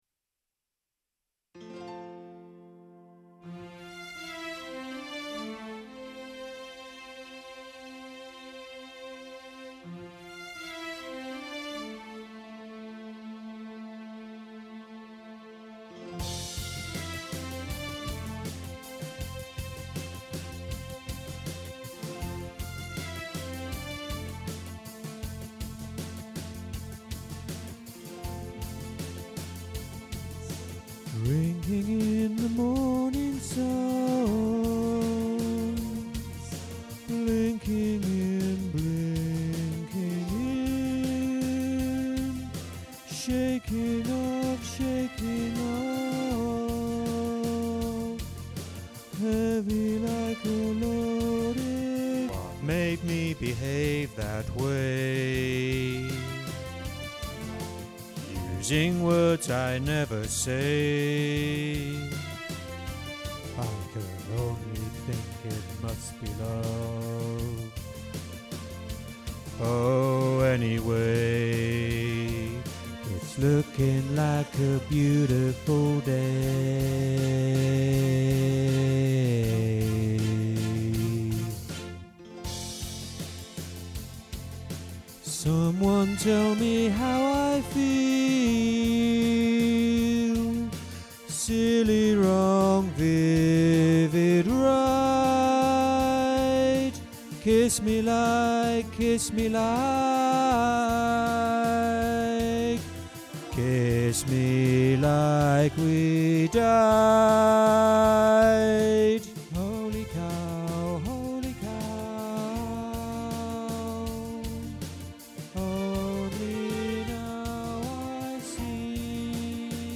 One-Day-Like-This-Tenor.mp3